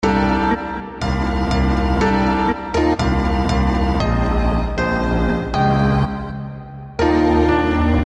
钢琴演奏
Tag: 120 bpm Chill Out Loops Piano Loops 1.35 MB wav Key : Unknown